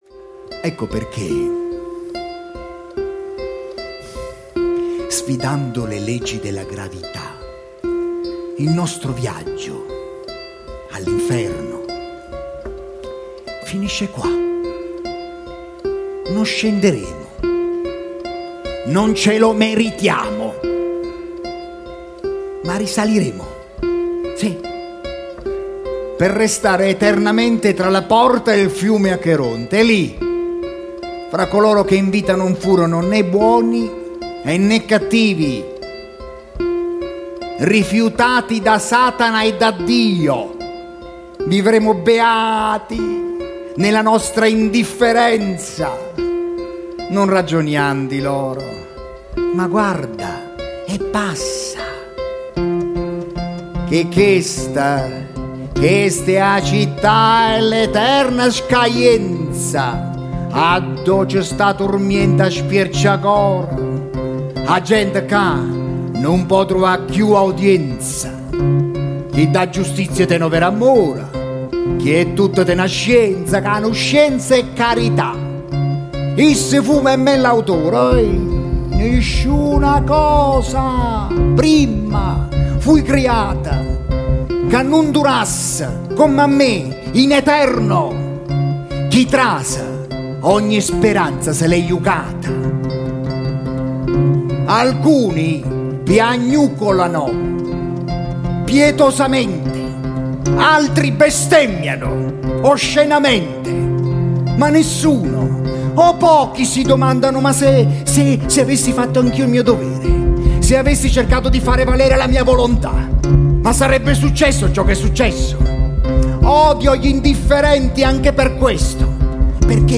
E poi, l’elemento essenziale, oltre ai testi, recitazione e musica: il pubblico, che diviene nel corso della rappresentazione, sempre più protagonista fino a creare una comunità.